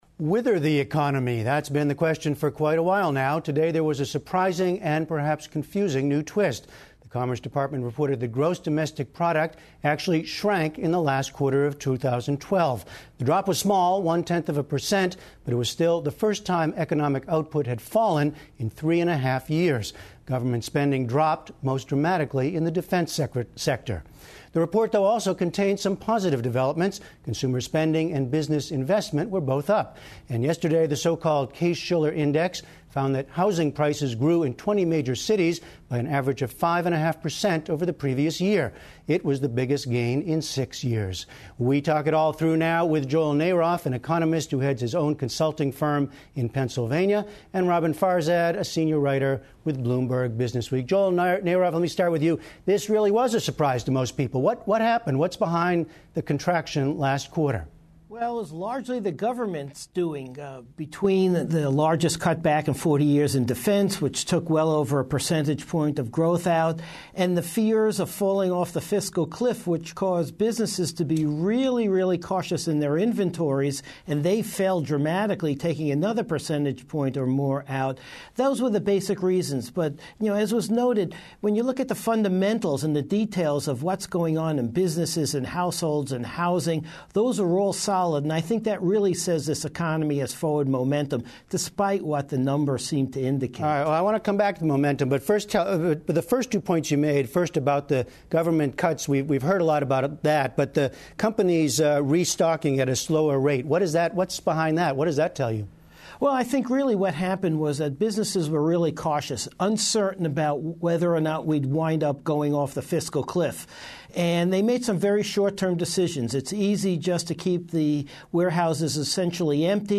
英语访谈节目:GDP有所下滑但经济势头仍然良好